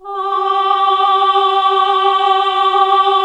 AAH G2 -R.wav